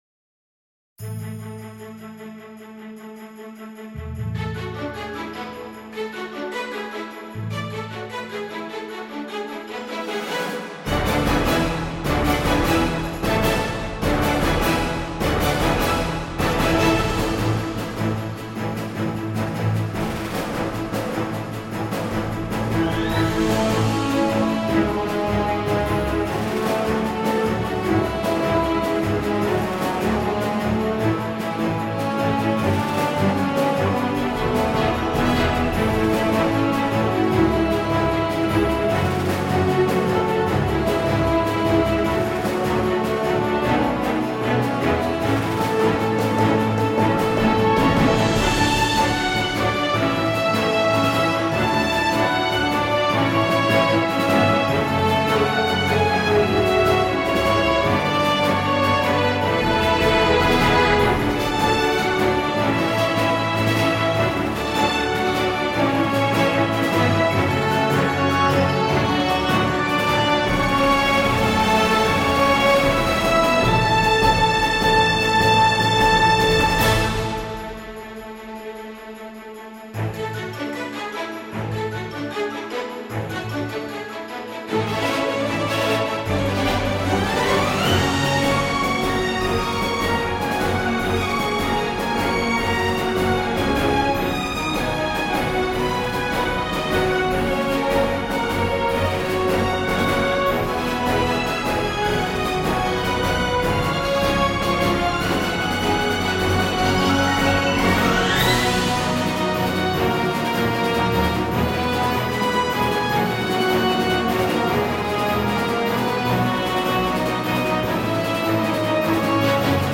Masterfully composed orchestral and electronic film scores.